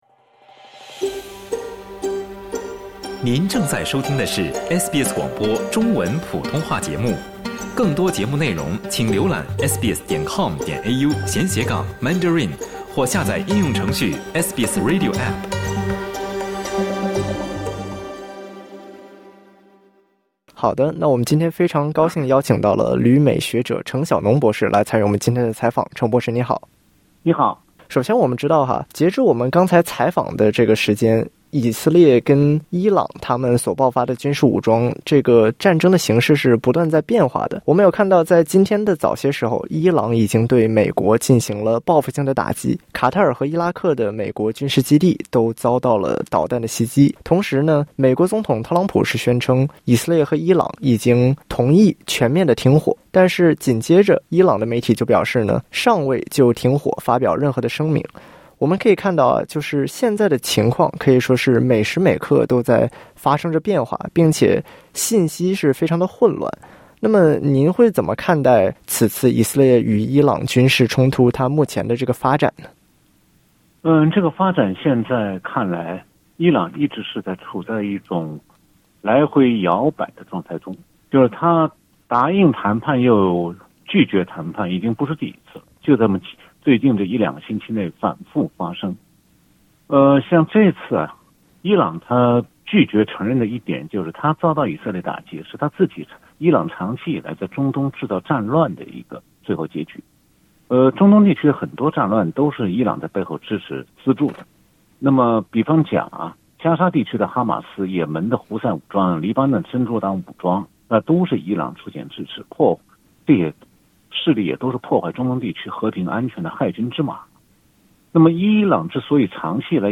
14:58 (File: Getty) SBS 普通话电台 View Podcast Series Follow and Subscribe Apple Podcasts YouTube Spotify Download (13.71MB) Download the SBS Audio app Available on iOS and Android 专家认为，本次中东地区冲突是伊朗长期以来在该地区制造战乱的结果。